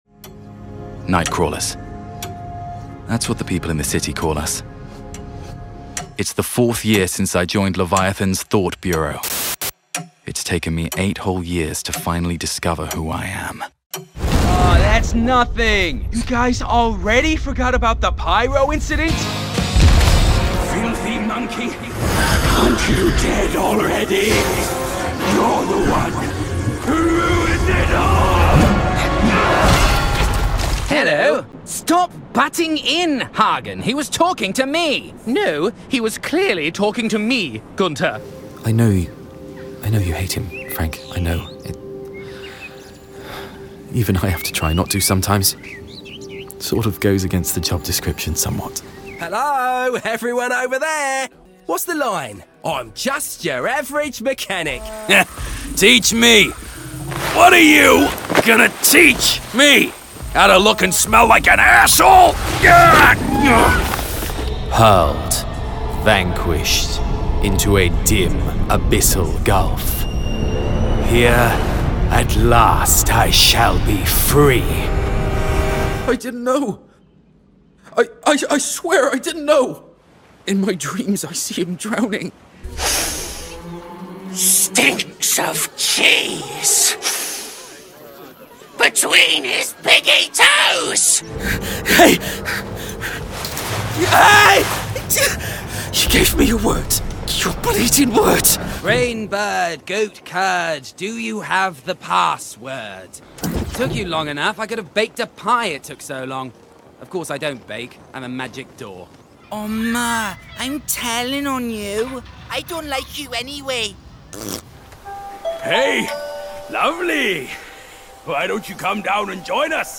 Gaming Showreel
Male
London
Neutral British
Bright
Friendly
Playful
Upbeat